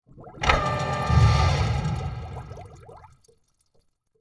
Drill.wav